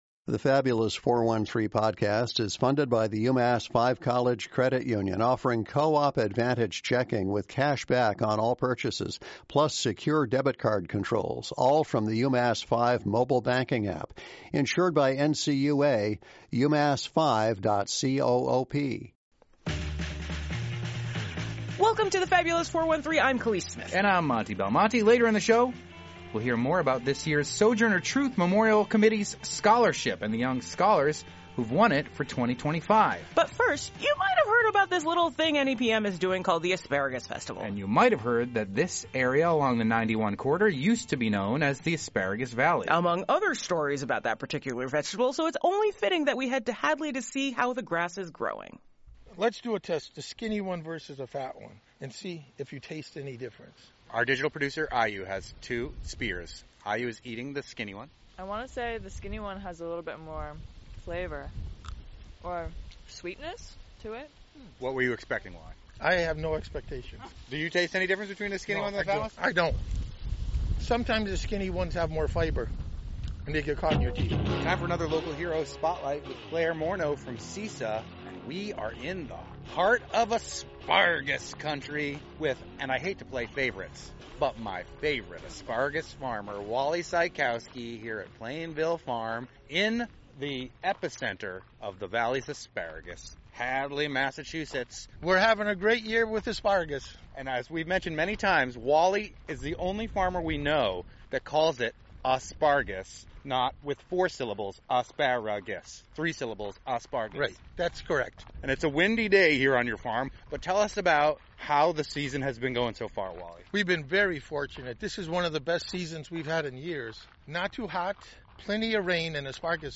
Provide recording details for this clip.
NEPM visit to Plainville Farm in Hadley